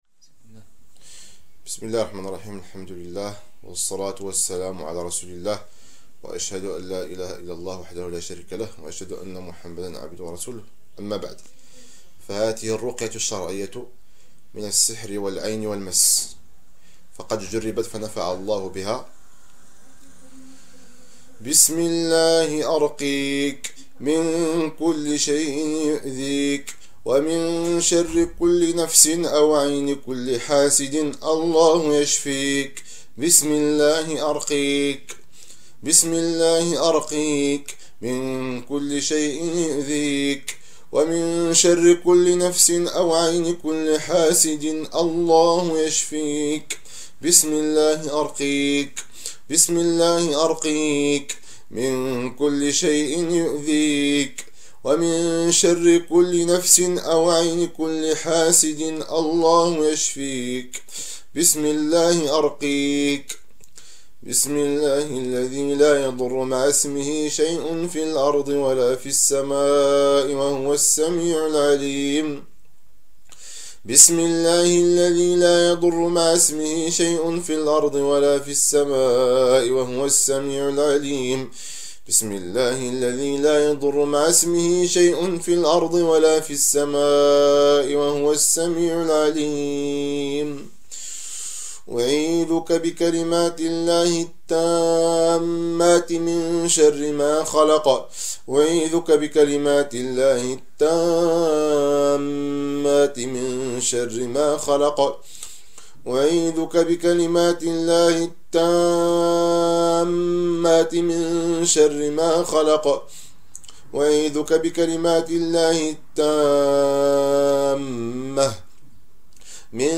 تلاوة مخصصة لآيات الرقية الشرعية لفك العين والسحر